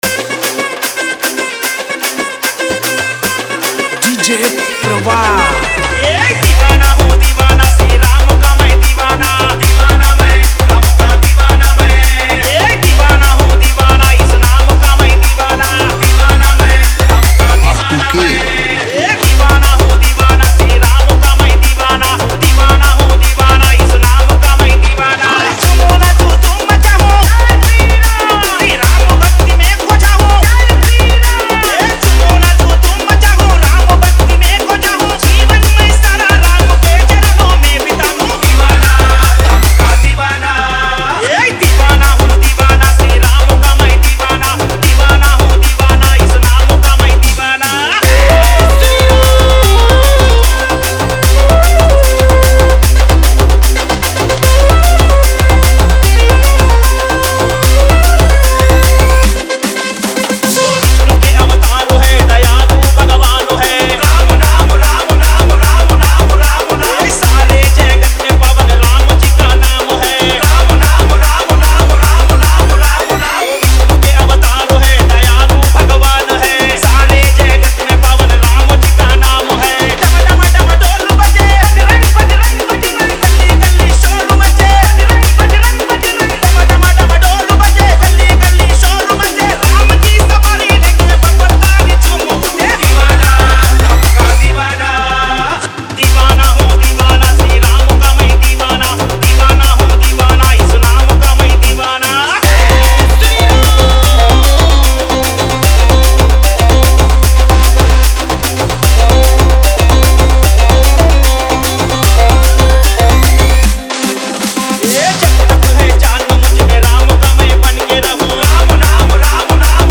Ram Navami Special Dj